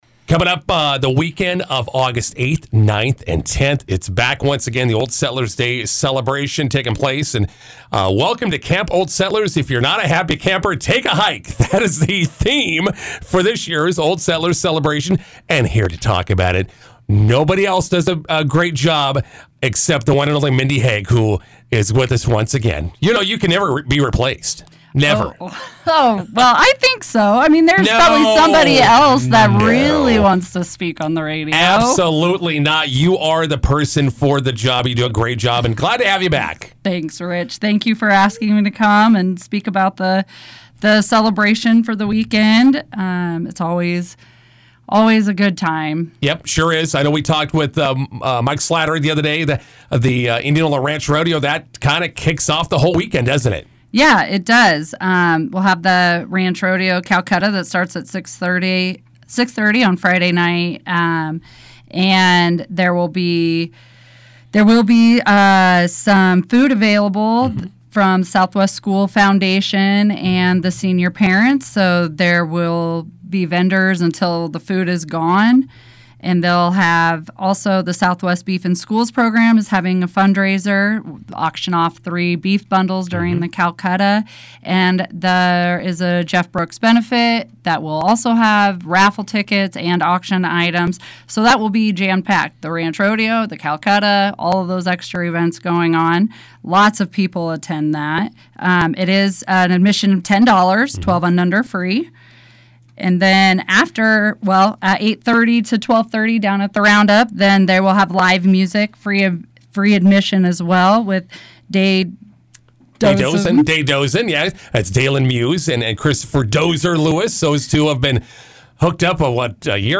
INTERVIEW: 2025 Old Settlers Day celebration in Indianola begins on Friday.